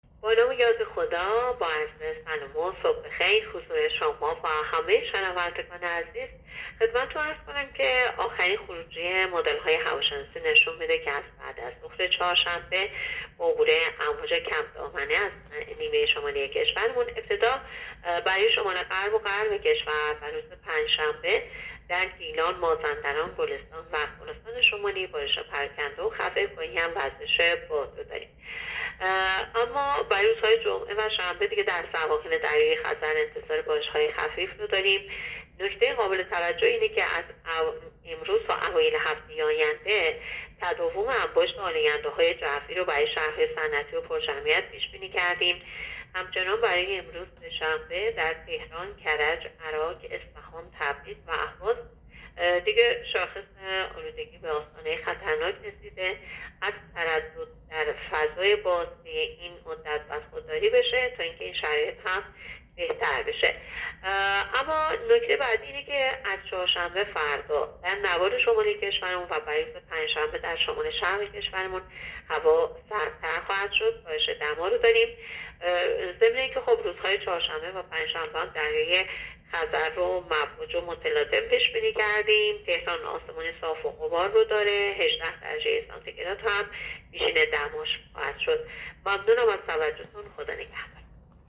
گزارش رادیو اینترنتی پایگاه‌ خبری از آخرین وضعیت آب‌وهوای ۴ آذر؛